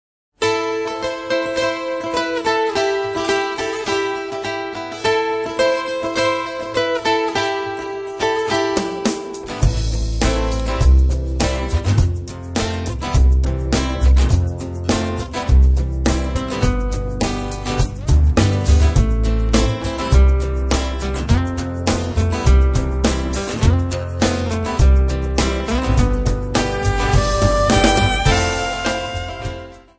Jolly contemporary religious Songs sung by Children.